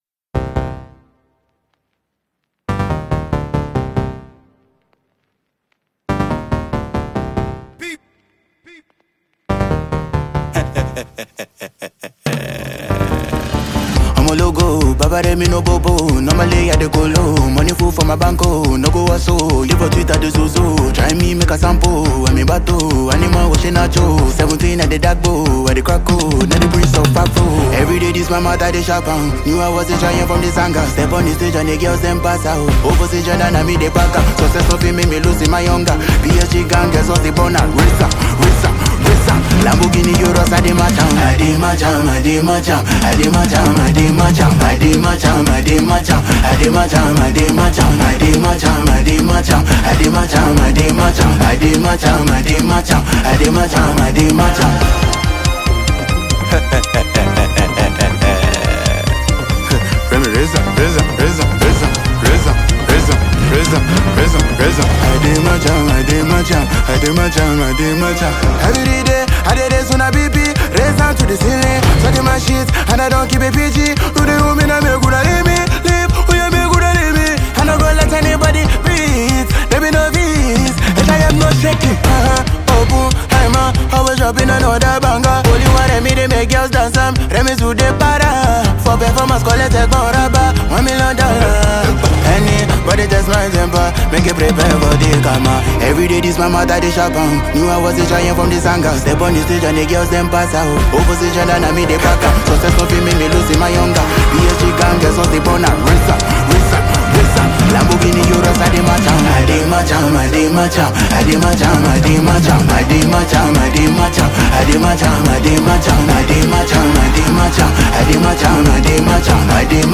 a heavyweight Nigerian afrobeat maestro